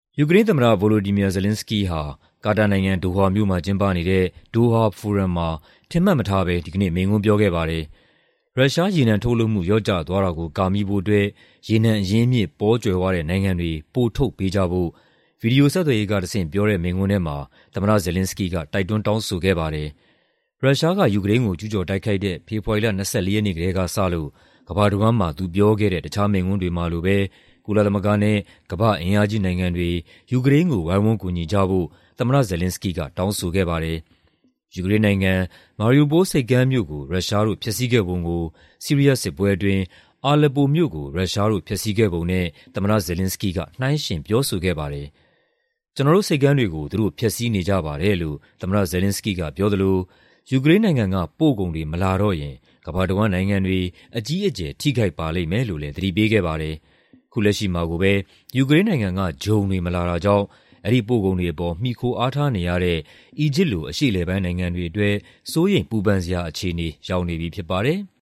ဒိုဟာဖိုရမ်မှာ ယူကရိန်းသမ္မတ မိန့်ခွန်းပြော